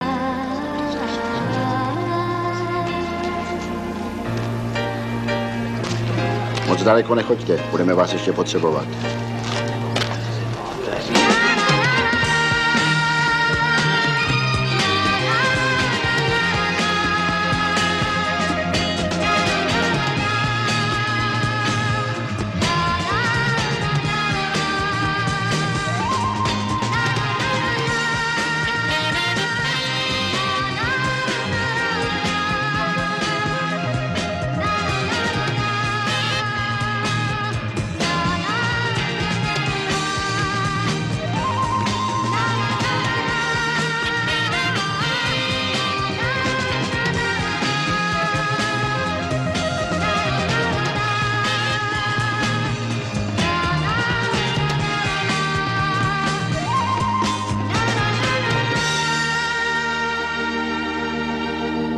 ale v té ukázce je v instrumentalní podobě.